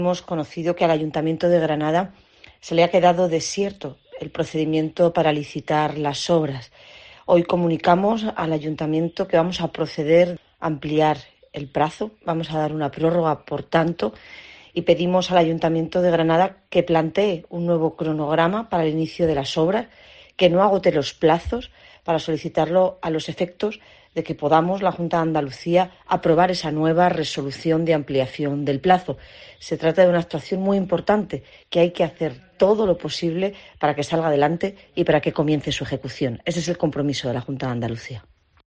Marifrán Carazo, consejera de Fomento